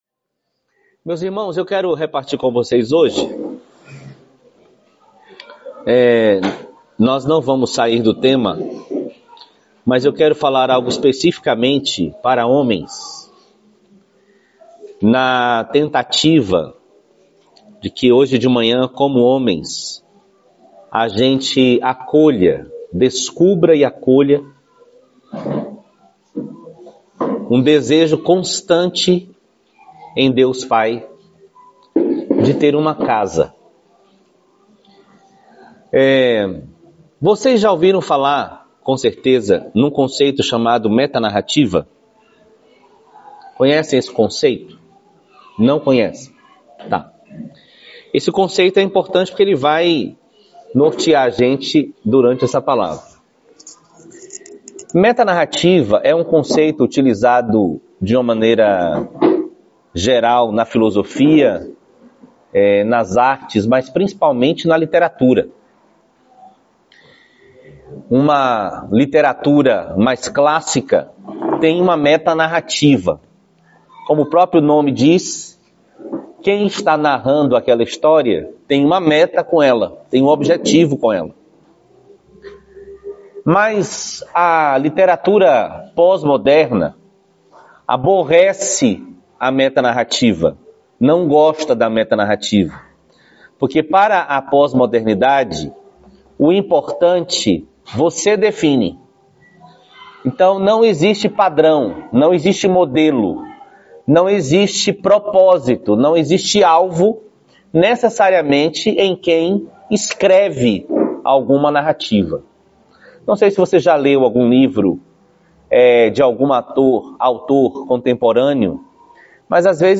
Palavra ministrada aos HOMENS no Encontro de Famílias 2025